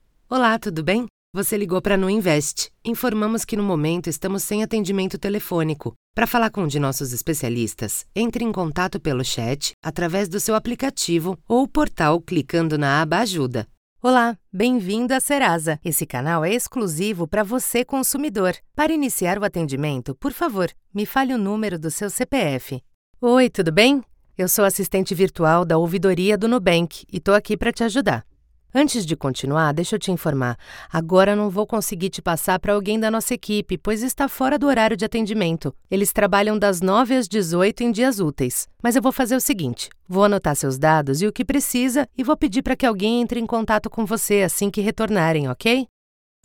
Feminino
ROBÔS NA TELEFONIA
Voz Varejo 00:46